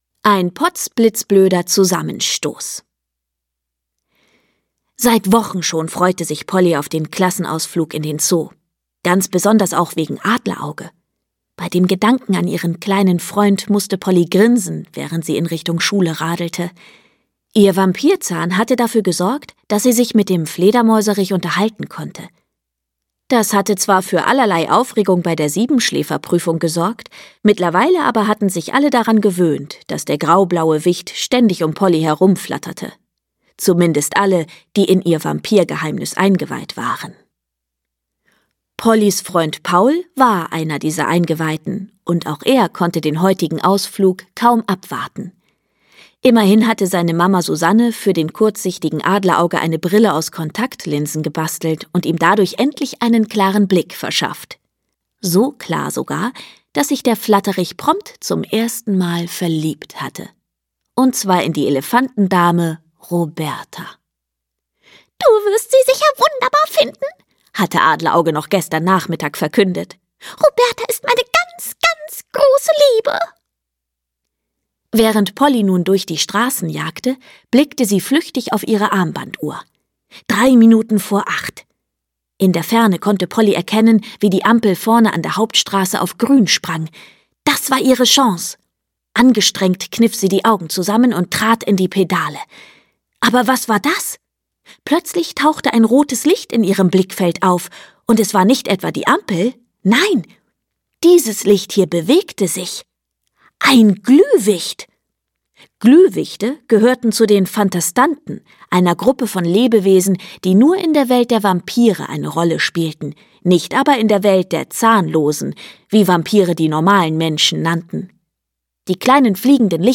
Polly Schlottermotz 2: Ein Rüssel kommt selten allein - Lucy Astner - Hörbuch